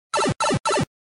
Play, download and share Super Mario Pipe original sound button!!!!
super-mario-pipe.mp3